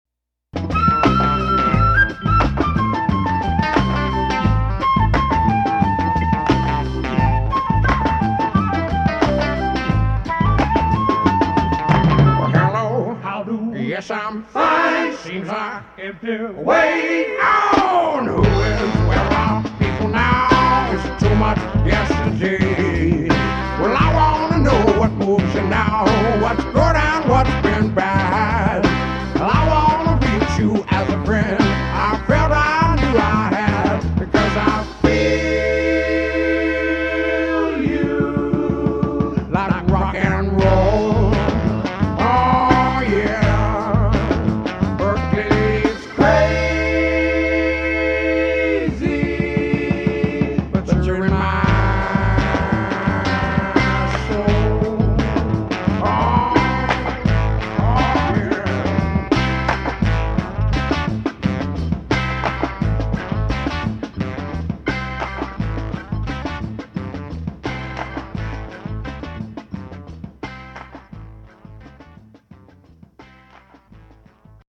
Cool groove
lead vocals
some fine 4 part harmony
flute solo